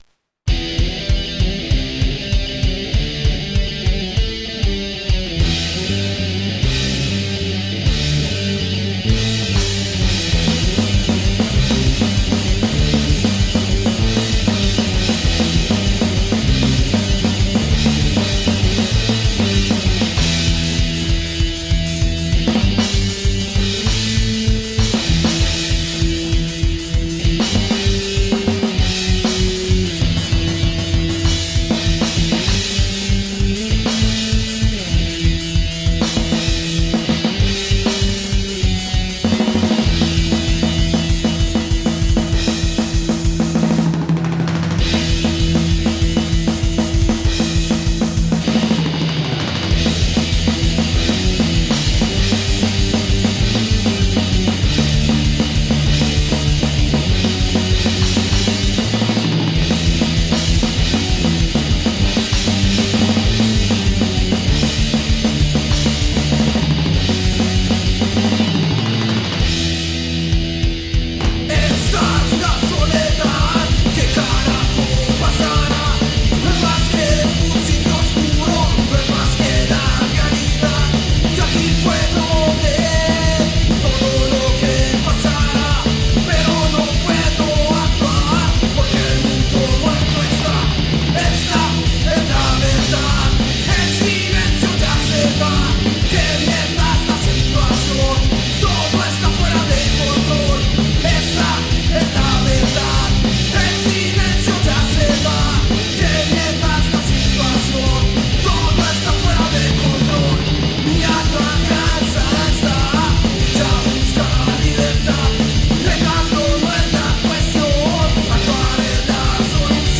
Skate-Punk Hardcore